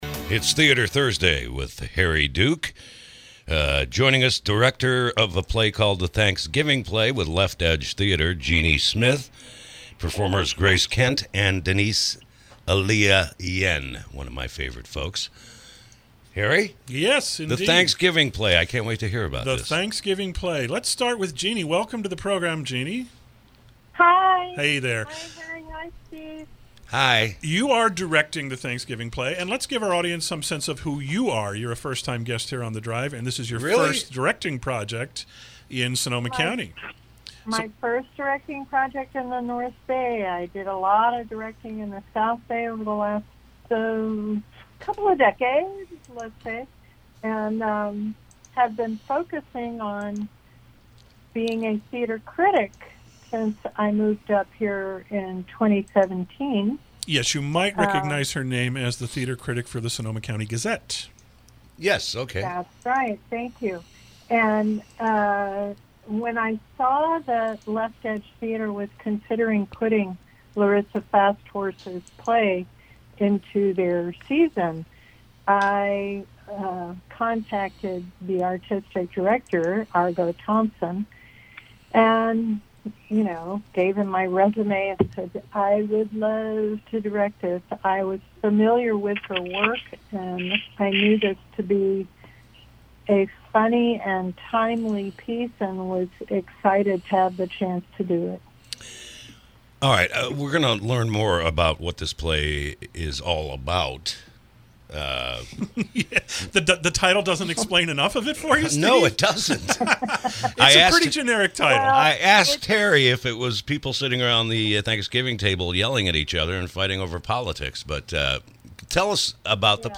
KSRO Interview – “The Thanksgiving Play”